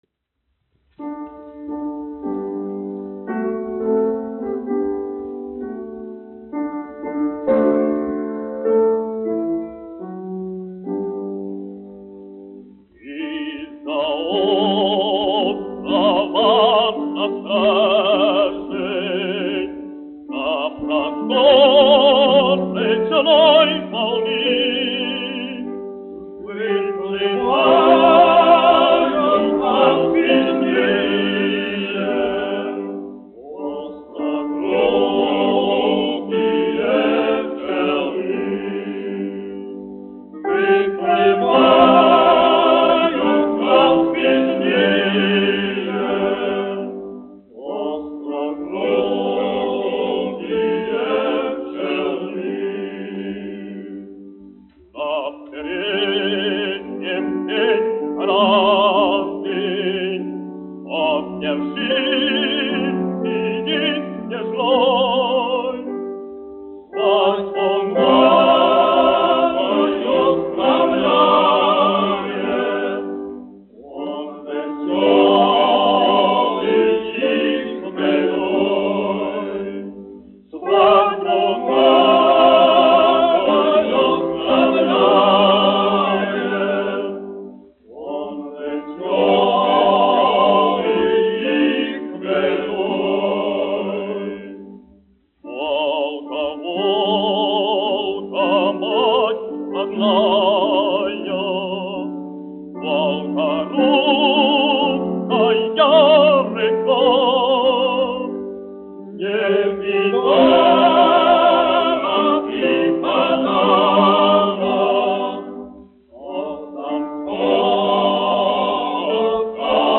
Volga...Volga : krievu tautas dziesma
1 skpl. : analogs, 78 apgr/min, mono ; 25 cm
Krievu tautasdziesmas
Vokālie seksteti
Latvijas vēsturiskie šellaka skaņuplašu ieraksti (Kolekcija)